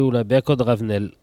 Patois
Catégorie Locution